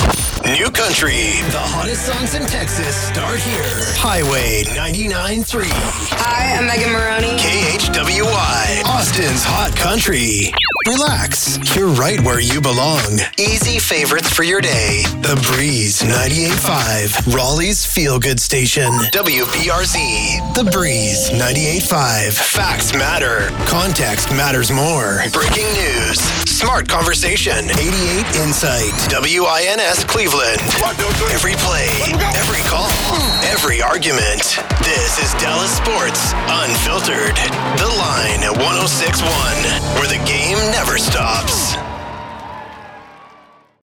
Male
My voice has "today's sound". It's young, fresh, cool, natural, conversational, relatable. I can do anything from laid back to hard sell and excited. I have a slight raspy and deep voice but can deliver upbeat young sounding copy with ease. I can do a cool, hip radio imaging voice from excited to smooth "mtv" type deliveries.
Radio Country/Ac/News/Sports